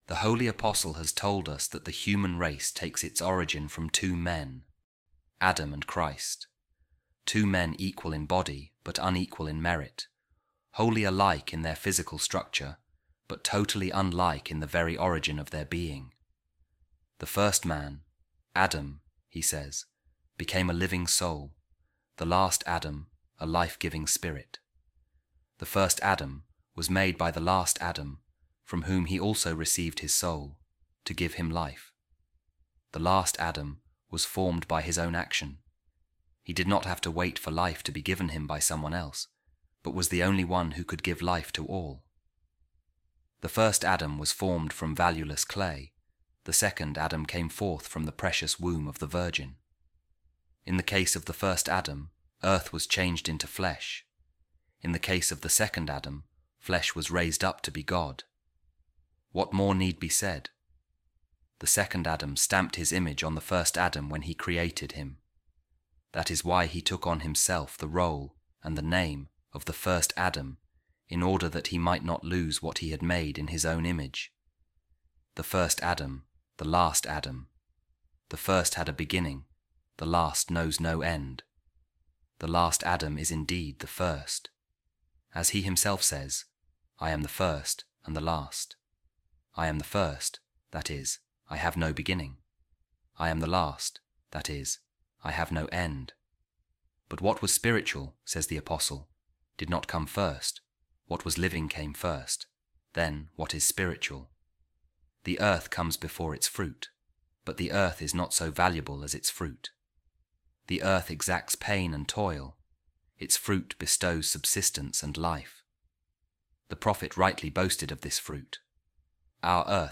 A Reading From The Sermons Of Saint Peter Chrysologus | The Word, The Wisdom Of God, Was Made Flesh